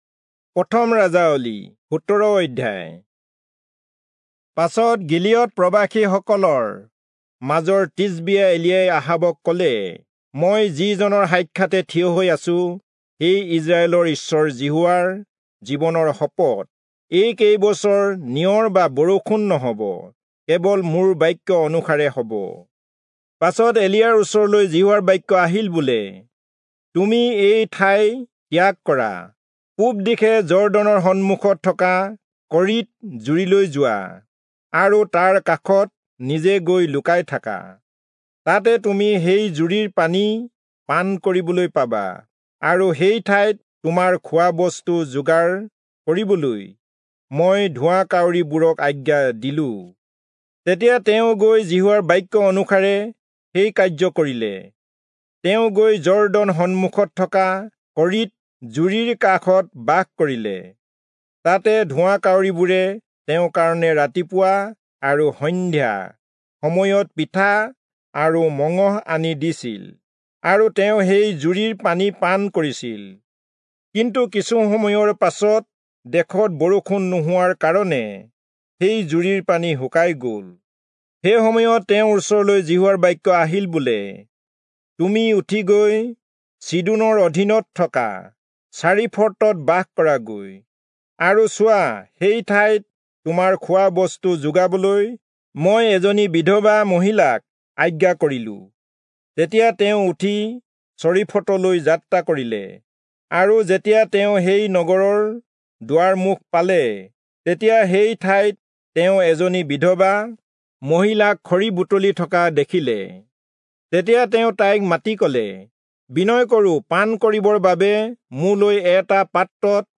Assamese Audio Bible - 1-Kings 16 in Irvml bible version